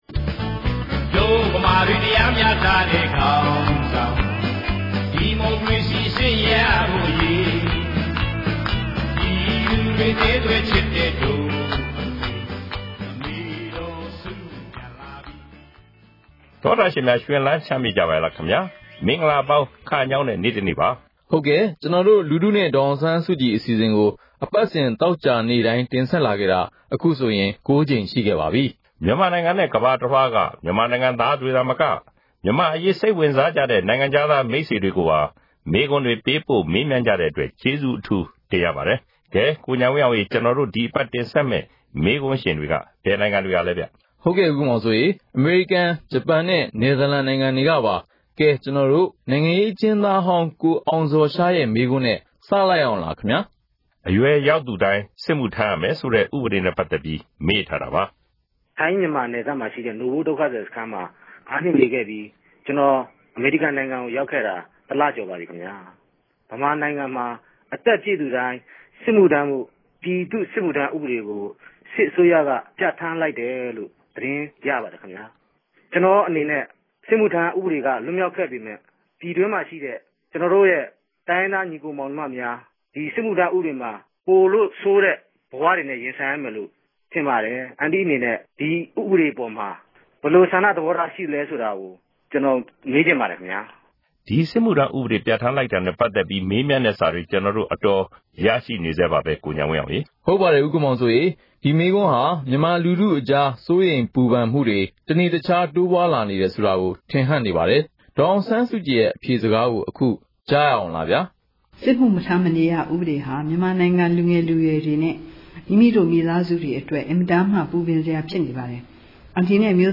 ‘လူထုနှင့် ဒေါ်အောင်ဆန်းစုကြည်’ အပတ်စဉ်အမေးအဖြေ
ဒီ အစီအစဉ်ကနေ ပြည်သူတွေ သိချင်တဲ့ မေးခွန်းတွေကို ဒေါ်အောင်ဆန်းစုကြည် ကိုယ်တိုင် ဖြေကြားပေးမှာ ဖြစ်ပါတယ်။